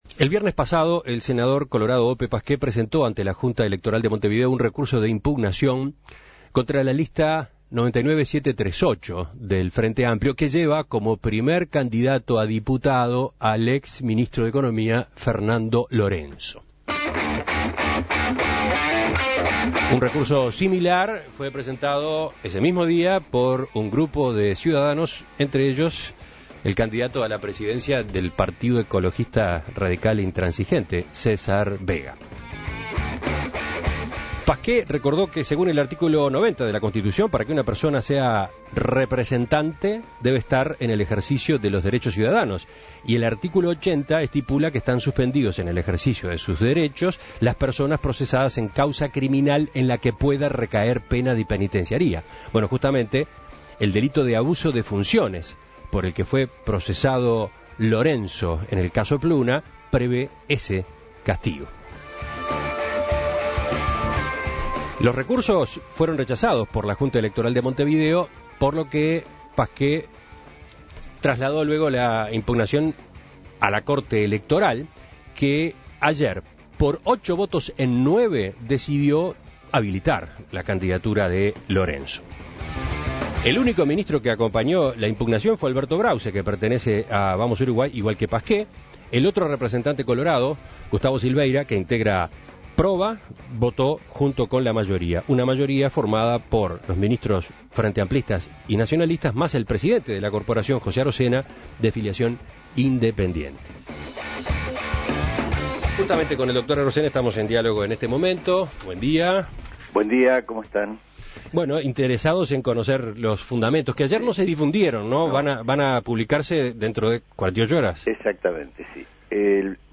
El senador colorado Ope Pasquet presentó un recurso de impugnación a la candidatura al Parlamento del ex ministro Fernando Lorenzo. Este recurso fue rechazado ayer por la Corte Electoral. En diálogo con En Perspectiva, el presidente de la Corte Electoral, José Arocena, hizo algunas consideraciones generales sobre el tema.